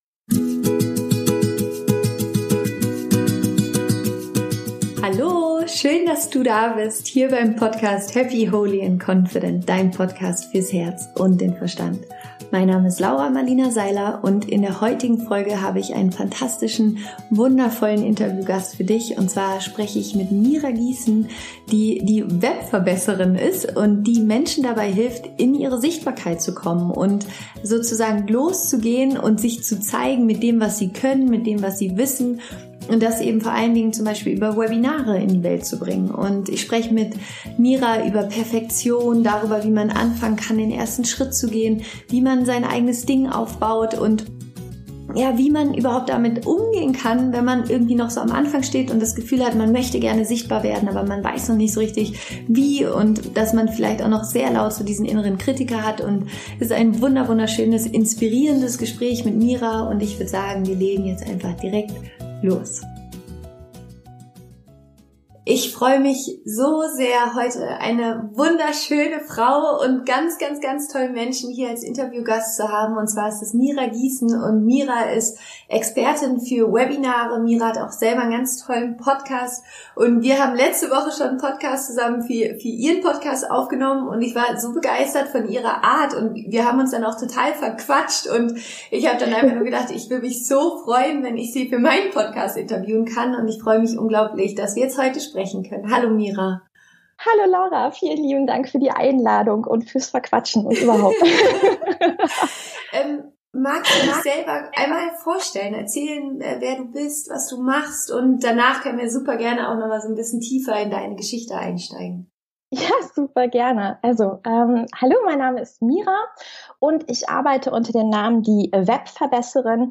Mein heutiger Interviewgast ist Expertin darin, Menschen dabei zu unterstützen, sichtbar zu werden und der Welt von ihrem Produkt oder ihrem Service zu erzählen und zu begeistern.